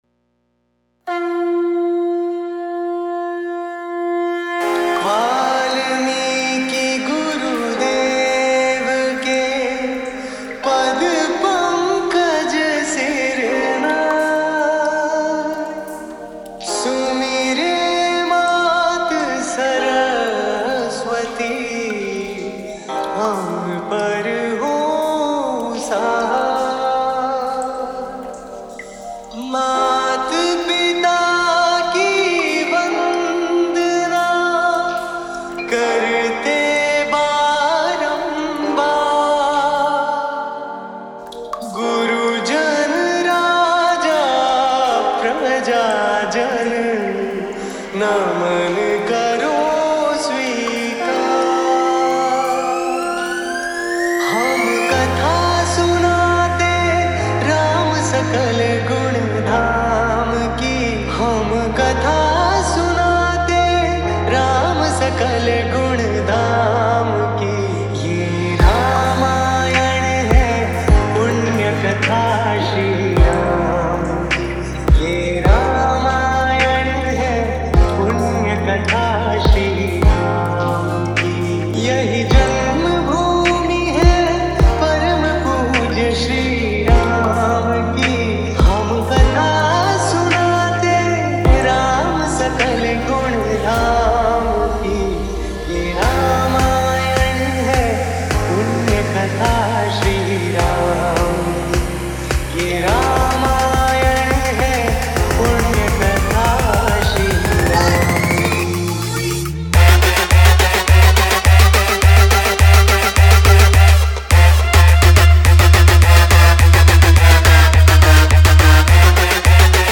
Bhakti DJ Remix Songs